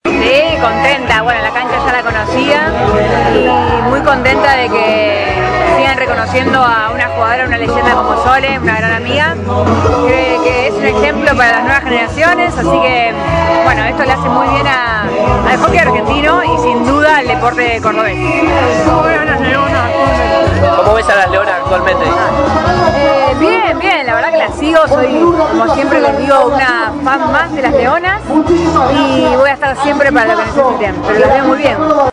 Audio de la nota con LUCIANA AYMAR: